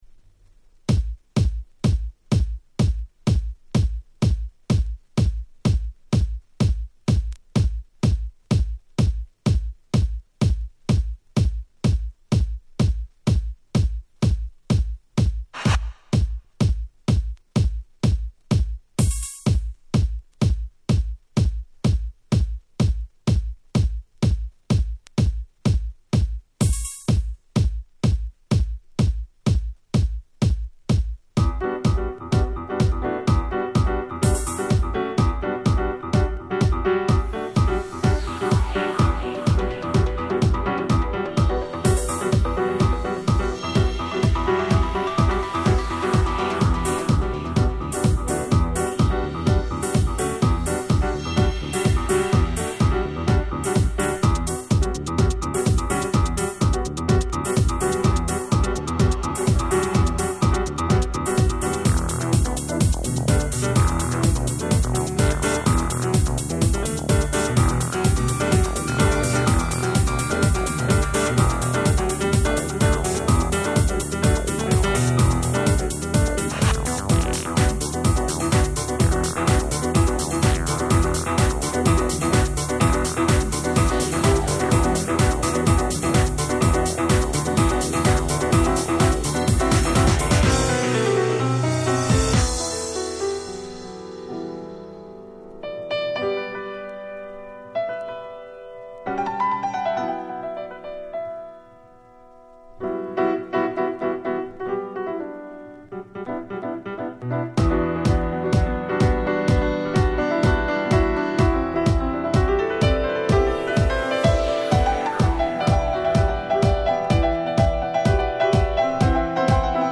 09' Nice Japanese House !!
綺麗なPianoの音色が縦横無尽に広がる凄く良い曲！